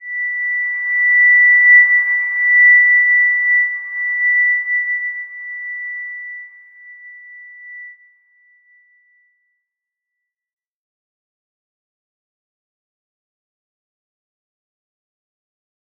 Slow-Distant-Chime-B6-mf.wav